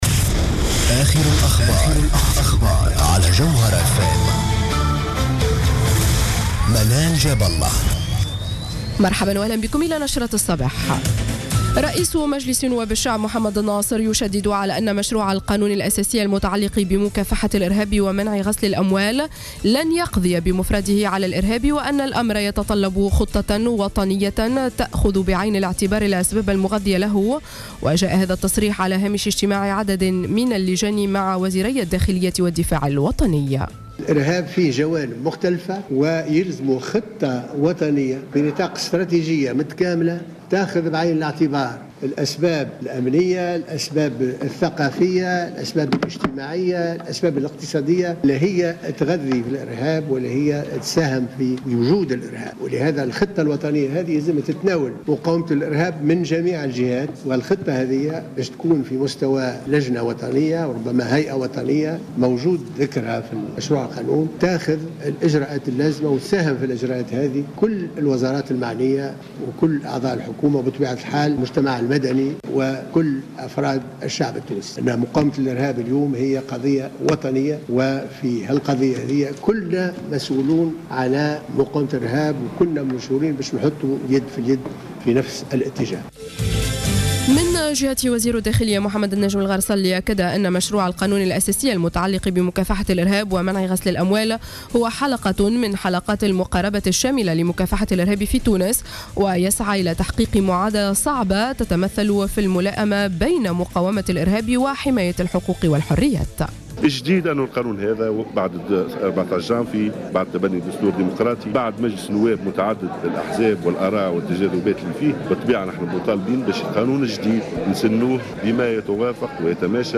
نشرة أخبار السابعة صباحا ليوم السبت 18 أفريل 2015